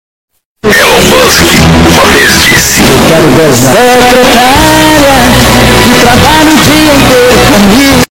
Loud Sound Effect Free Download
Loud